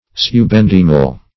Subendymal \Sub*en"dy*mal\, a.